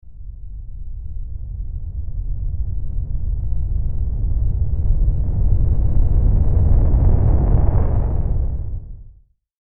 環境音 | 無料 BGM・効果音のフリー音源素材 | Springin’ Sound Stock
地響き1.mp3